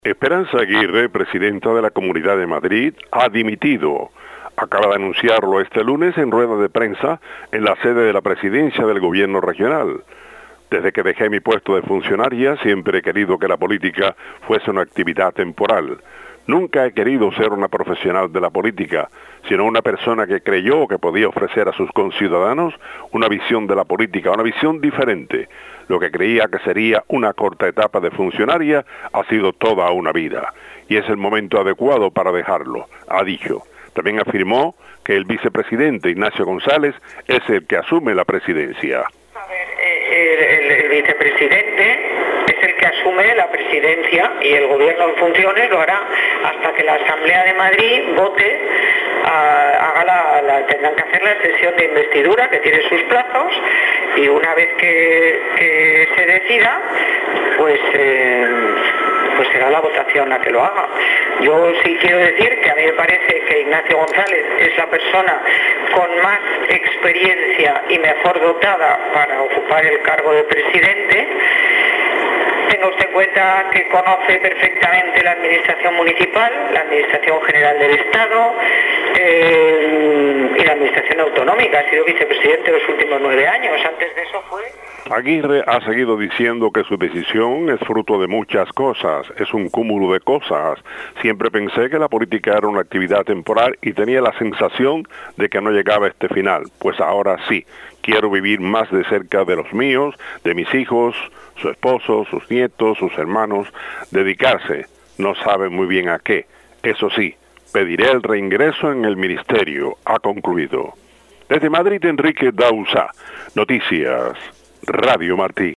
En una conferencia de prensa, Aguirre señaló que deja el cargo de diputada regional y de presidenta del Gobierno de la Comunidad Autónoma de Madrid tras 29 años dedicada a la política.